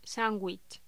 Locución: Sandwich
voz
Sonidos: Voz humana